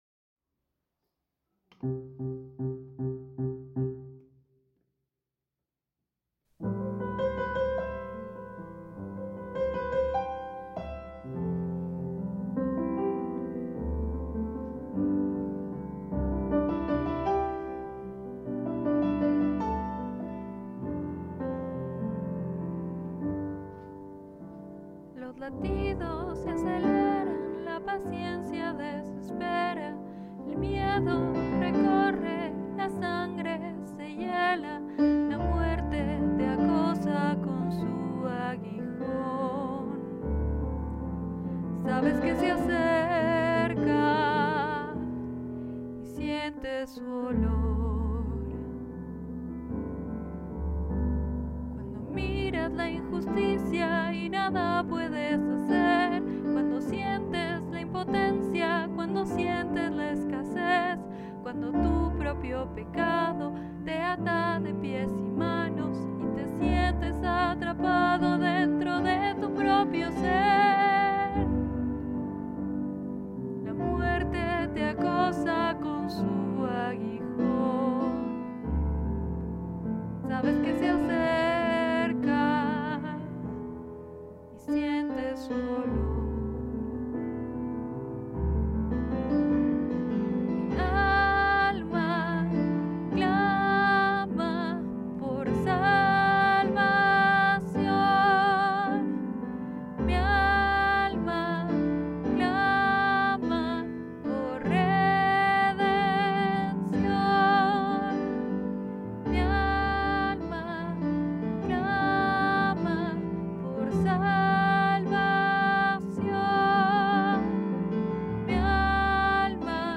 • Técnica: Balada fúnebre.